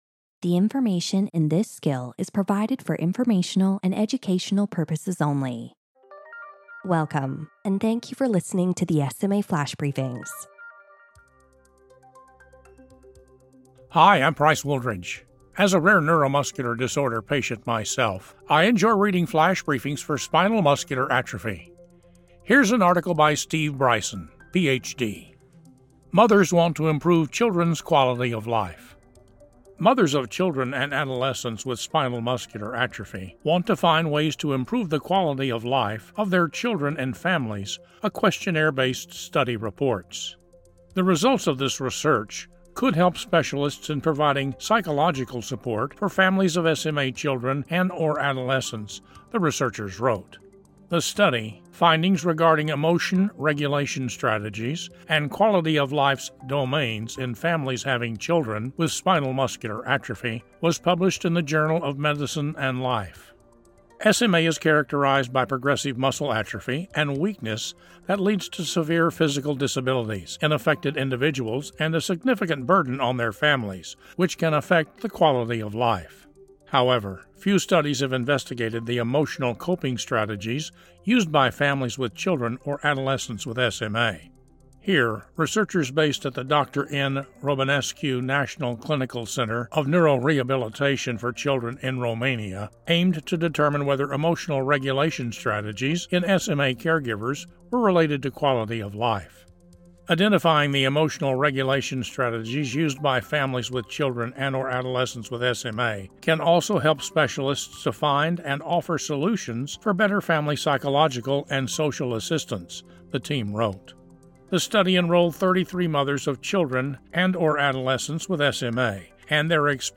reads a column